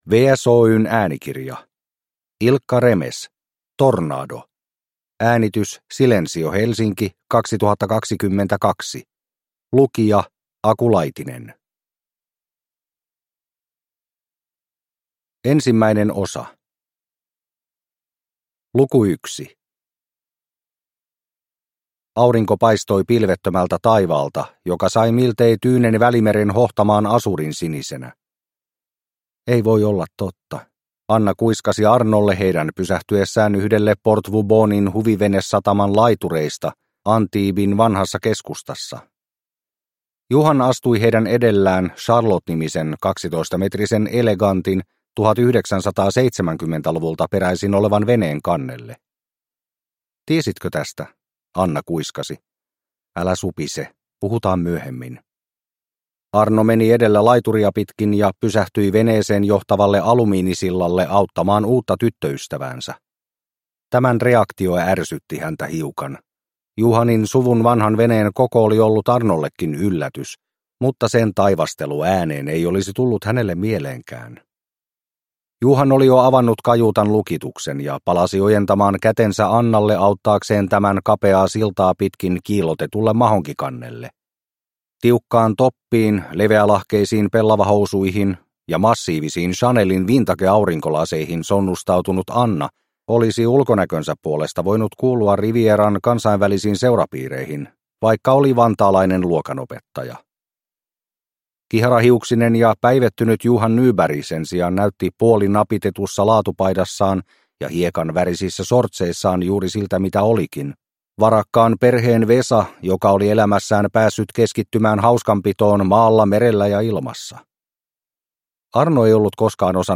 Tornado – Ljudbok – Laddas ner